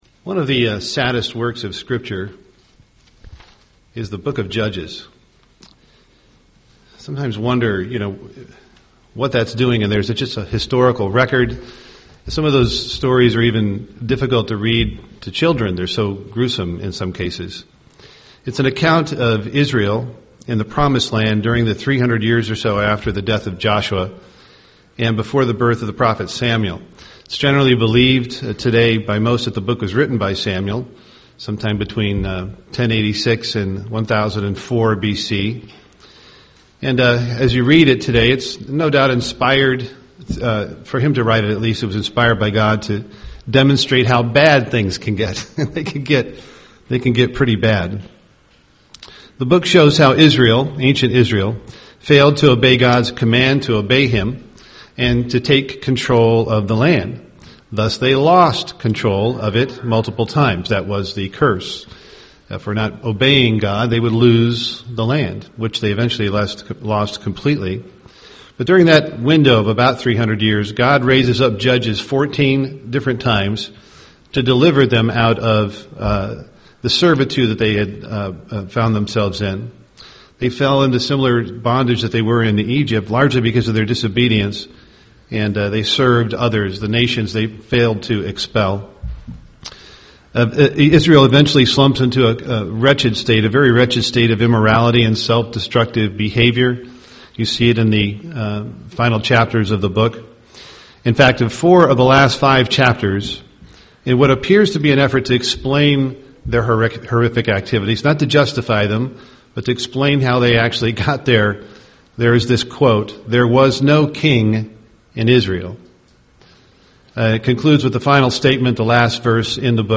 UCG Sermon serve God loyalty to God Studying the bible?